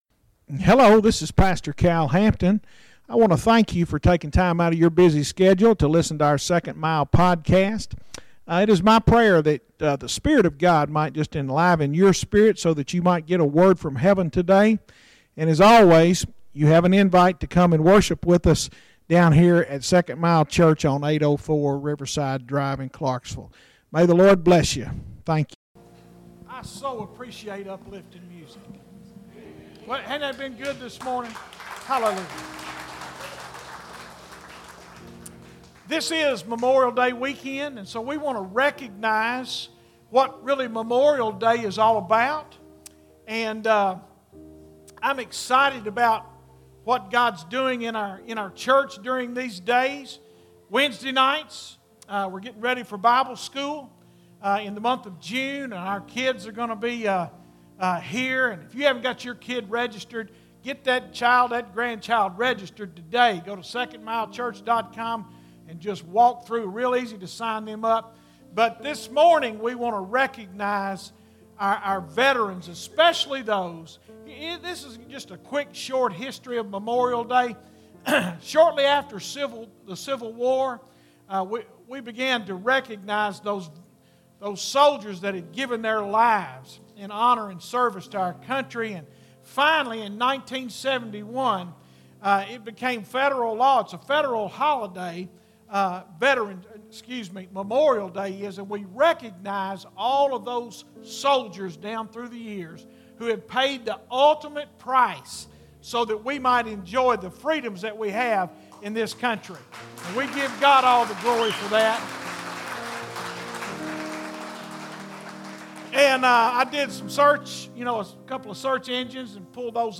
Sermons Archive - Page 138 of 311 - 2nd Mile Church